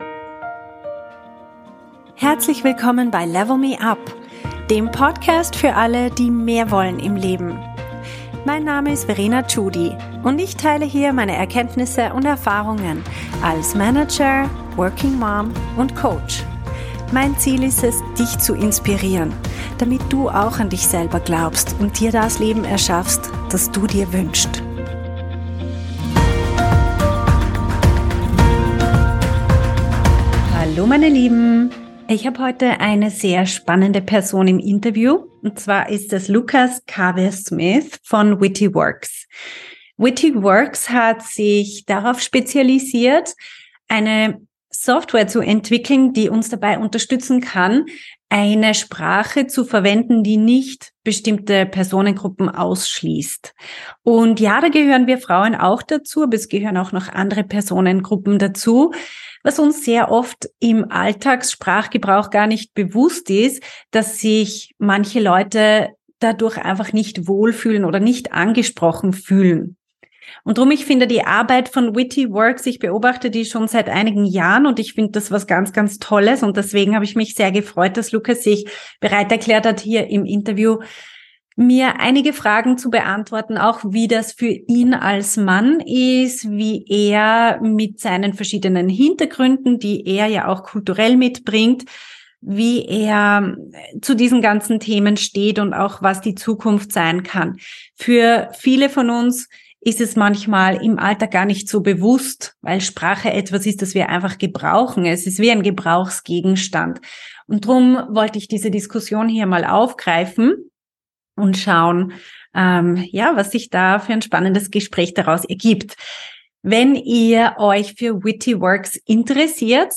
Inclusive Language - Interview